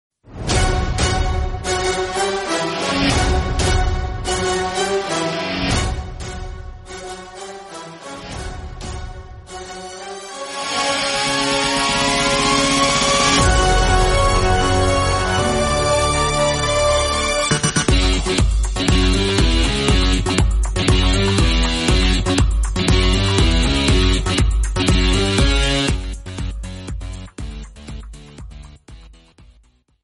Instrumental ringtone free download